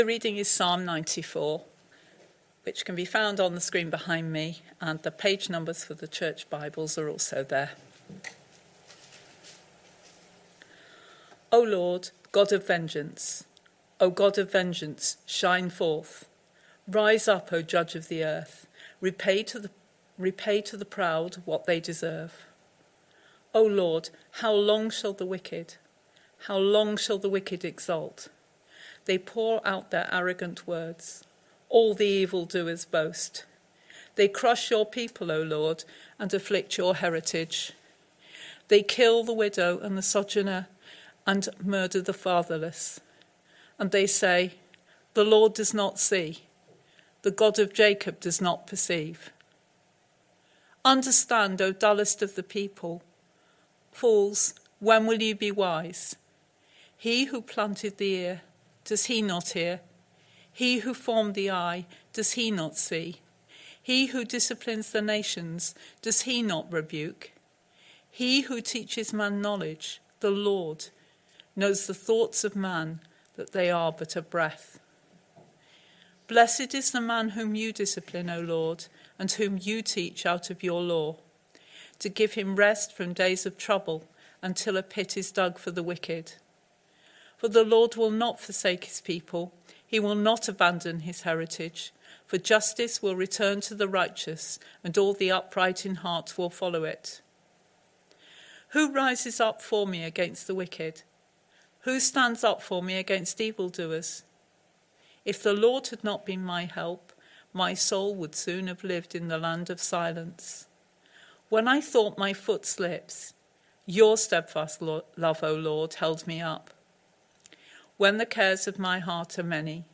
Message
Sunday PM Service Sunday 2nd November 2025 Speaker